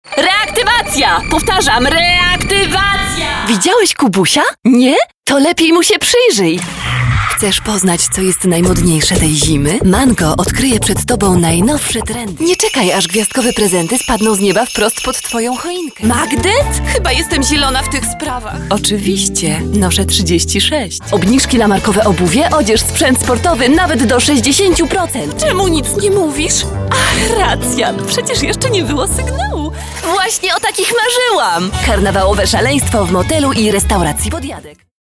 Stimme: jung, fröhlich, dynamisch, warm, klar, freundlich, ruhig, verträumt
Sprechprobe: Werbung (Muttersprache):
polish voice over artist